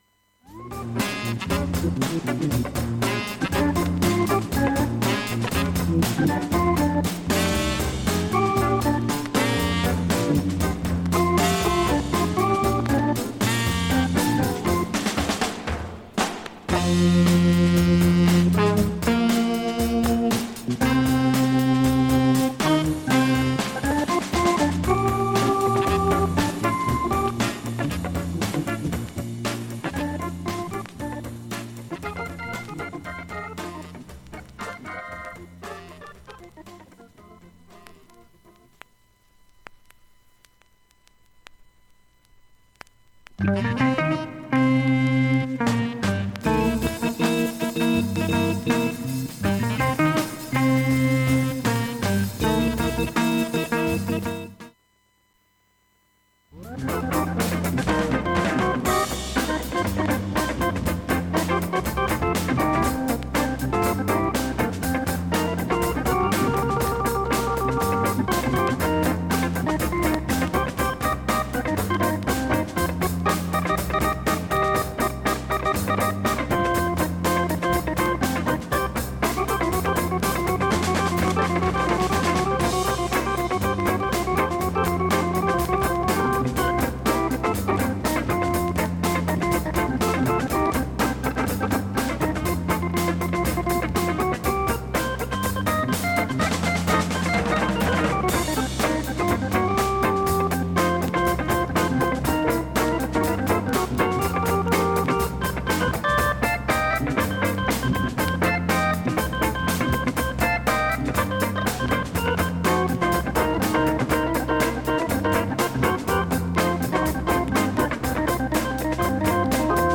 音質良好全曲試聴済み。
現物の試聴（上記録音時間７分）できます。音質目安にどうぞ
ほか5回までのわずかなプツ４箇所
単発のわずかなプツ２２箇所
レア・グルーブ A to Z掲載